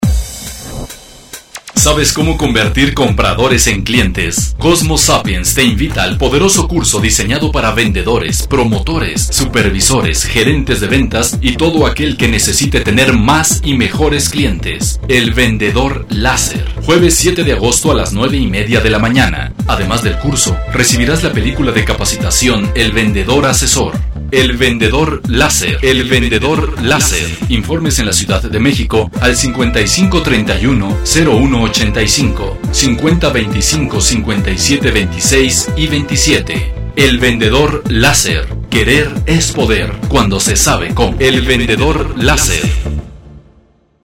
Locutor profesional con veinte años de experiencia.
Sprechprobe: Werbung (Muttersprache):